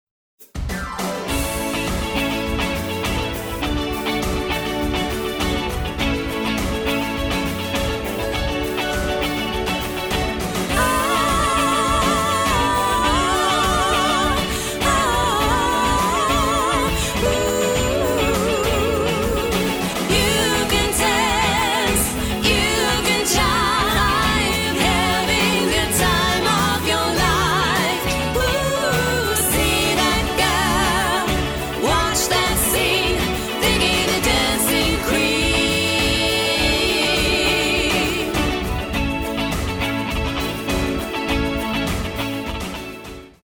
Tribute Acts